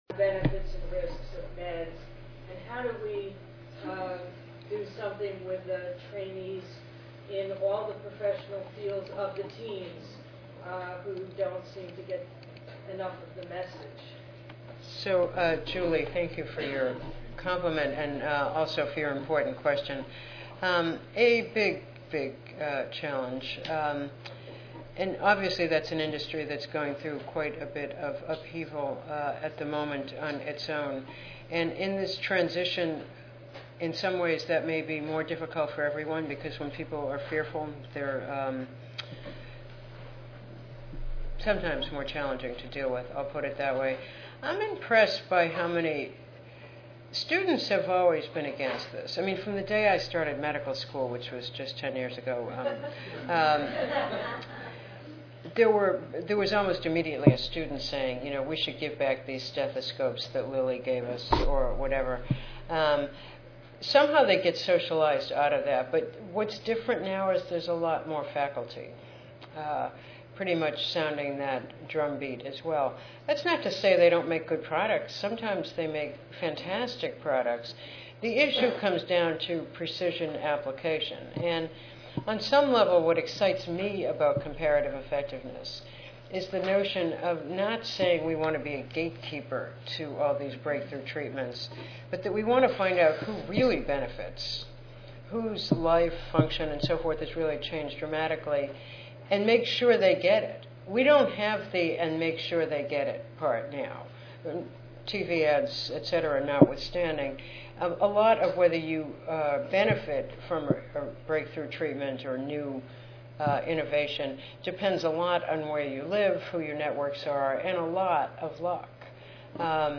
Dr. Clancy will bring this critical thinking along with her pointed wit and dry humor to the 2011 Donabedian Lecture. She will reflect on how quality has (and has not) improved during her two decades at AHRQ.